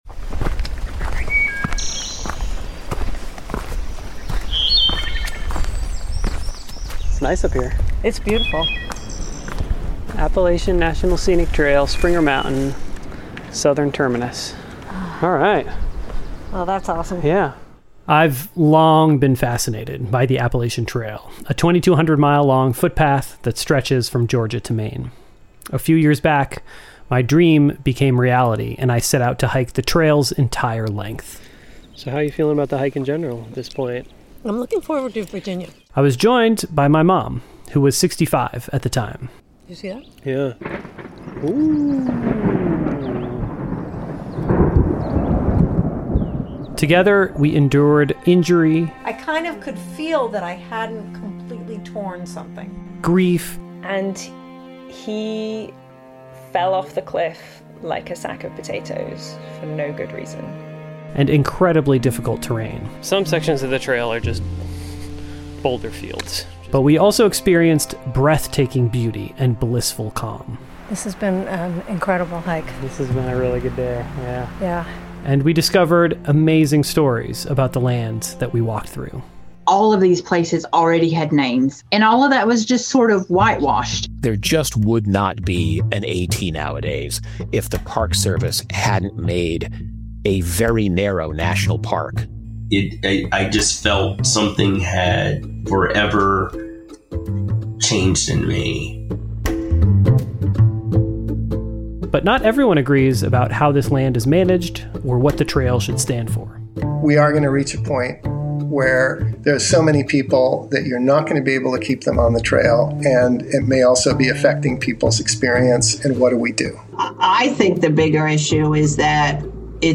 G.O. Get Outside: The Podcast is a radio-style interview show for people who want to spend more time outdoors. Each episode of G.O. delves into the outdoor lifestyle of some everyday person who probably has more in common with you than you think.